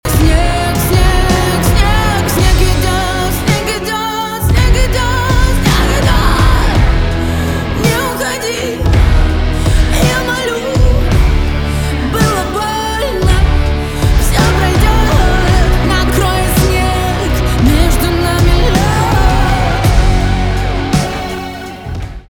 поп
грустные
барабаны , гитара , качающие